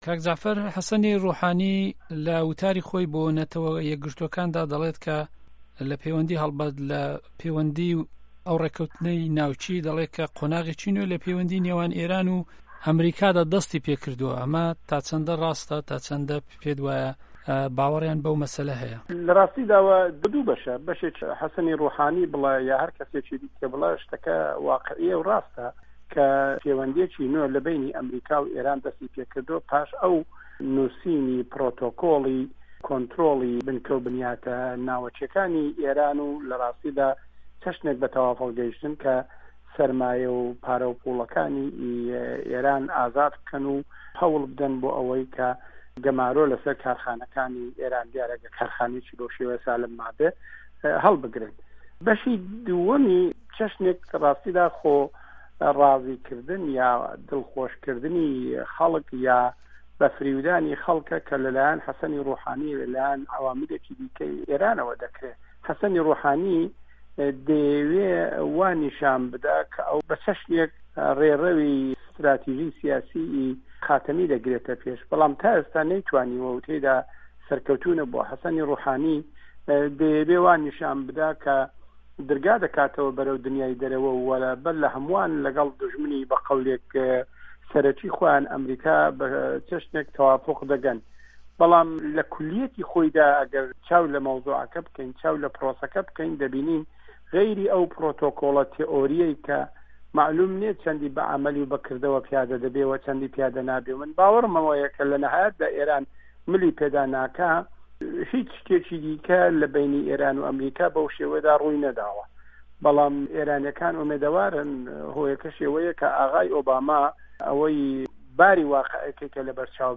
وتووێژی